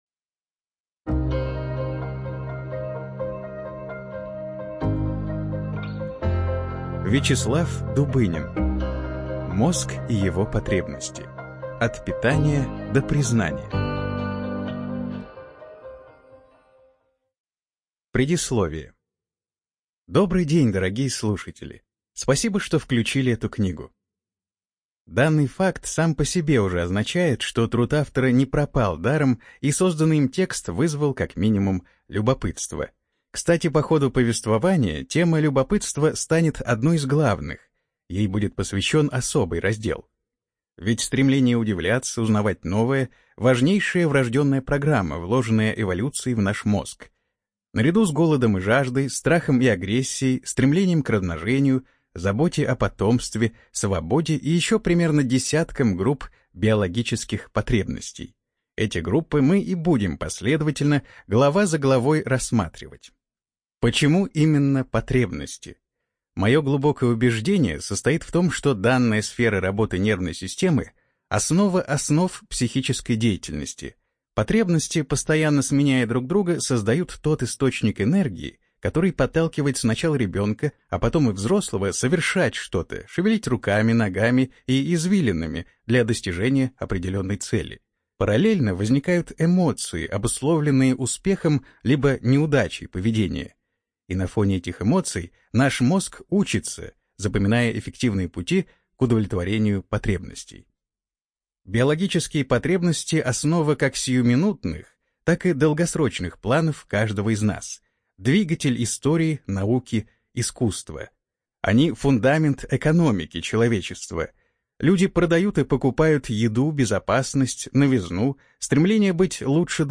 ЖанрНаучно-популярная литература